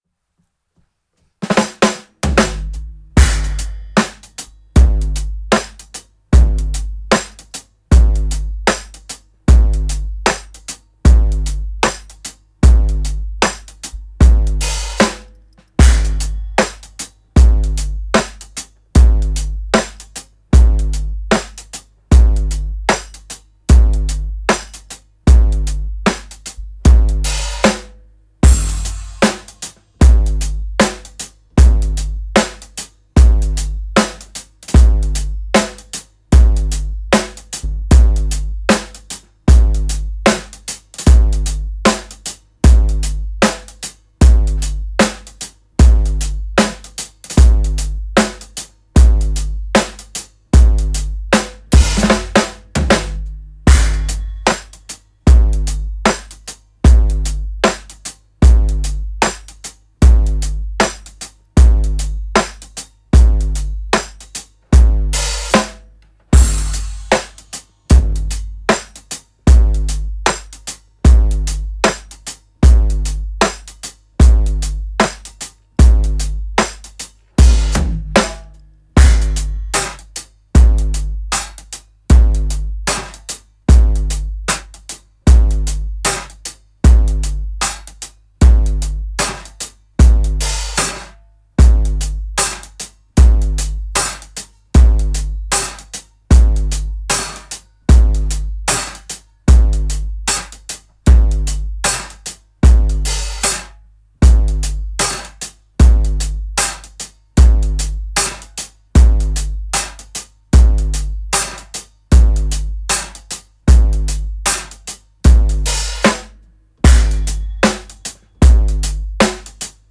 Wonky Pop
Genre:Wonky Pop, Hip-hop
Tempo:76 BPM (4/4)
Kit:Audition Japanese vintage 16"
Mics:14 channels